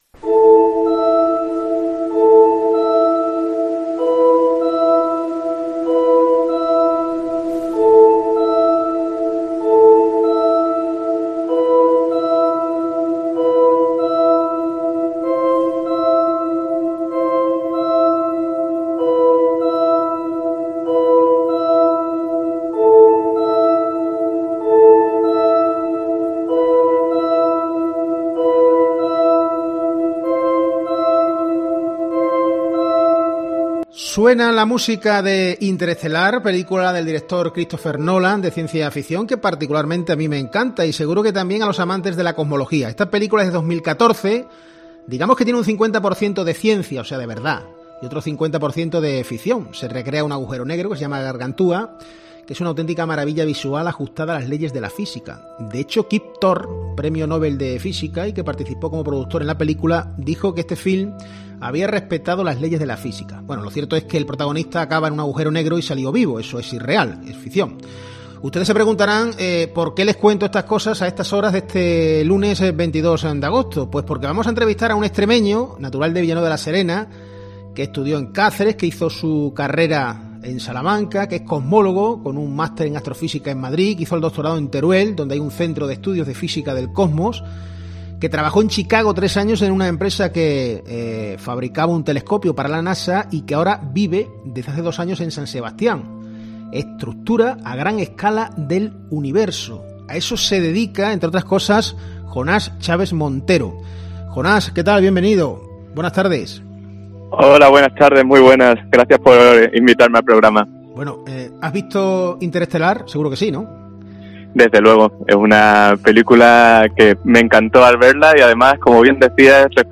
En COPE Extremadura hemos entrevistado a un astrónomo extremeño